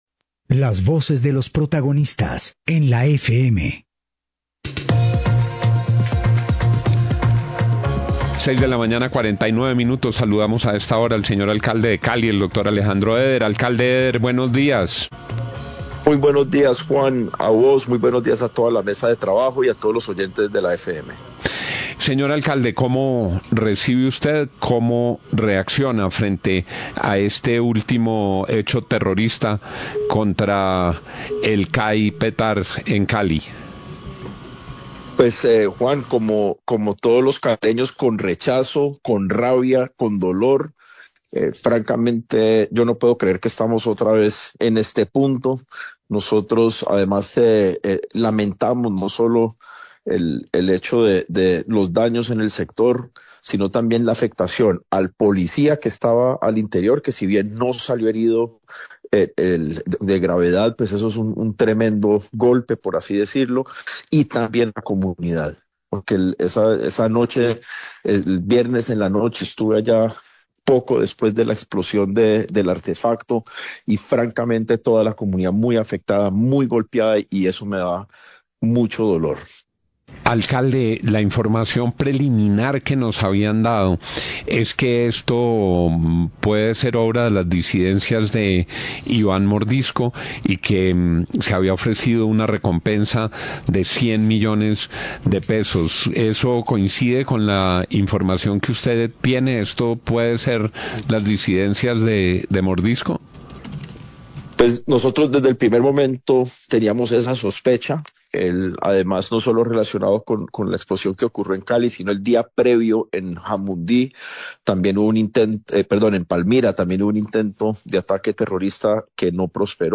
Radio
entrevistas